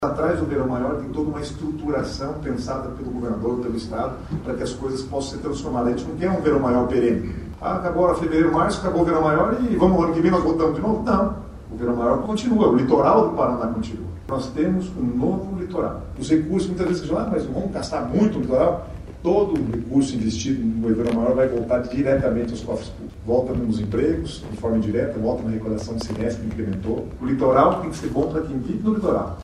Sonora do secretário do Planejamento, Guto Silva, sobre o balanço do Verão Maior Paraná 2023/2024